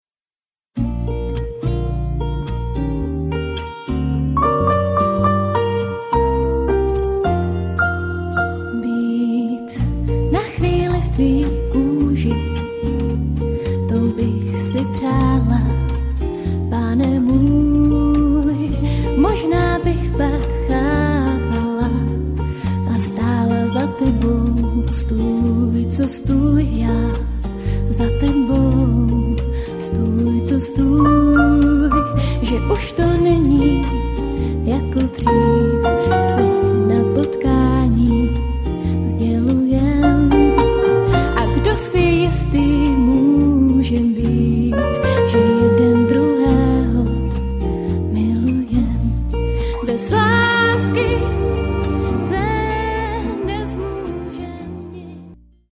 Žánr: pop, folk, jazz, blues.